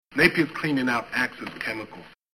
As an aside, it obviously doesn't come across at all in still pictures, but every level is peppered with voice clips direct from the film.
They also come packaged with more voice-over for you to 'enjoy'.